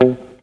RIFFGTR 09-R.wav